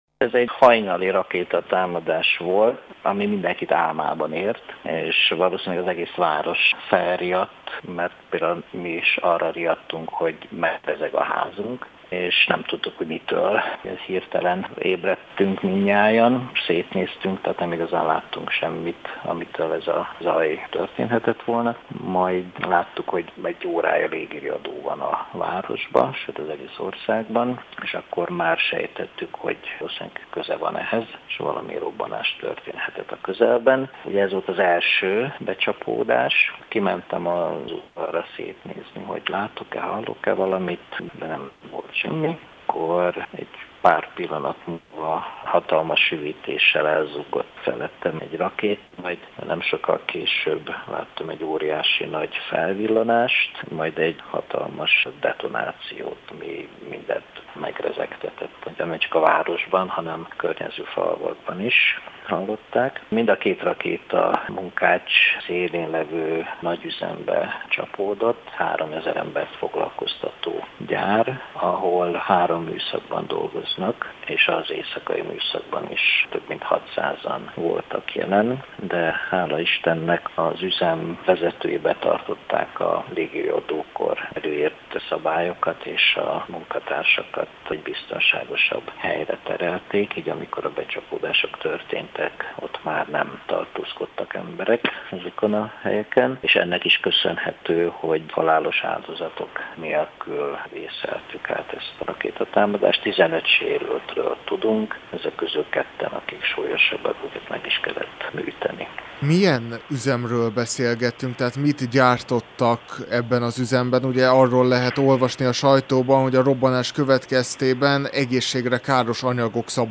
Helyszíni beszámoló a munkácsi támadásról